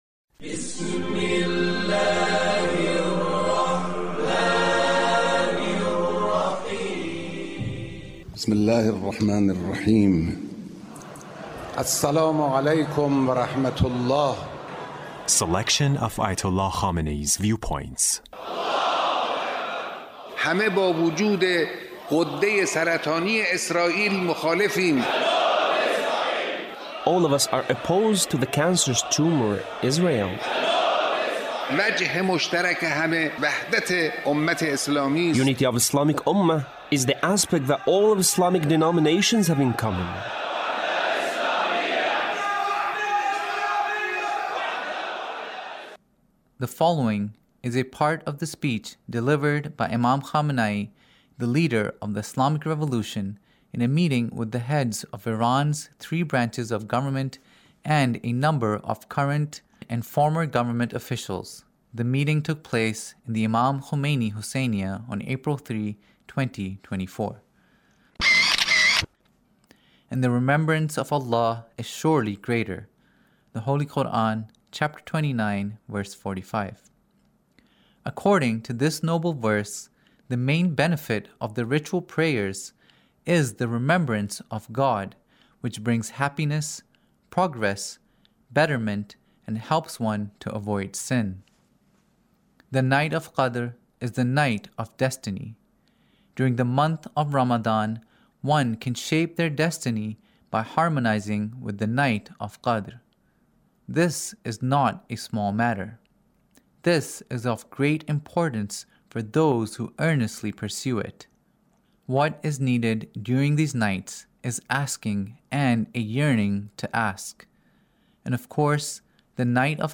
Leader's Speech in a Meeting with the Three Branches of Government The Important of Fasting